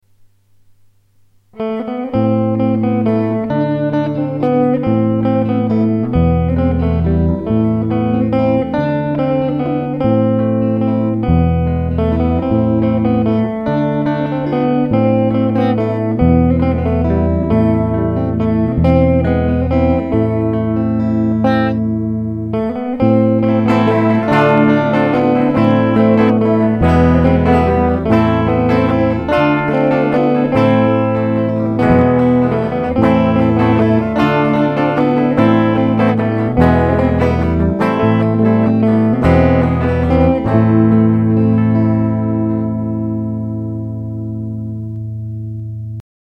חשמלית.
לענ"ד קיימים כמה זיופים.
נשמע כמו מתקופת ספרד העתיקה.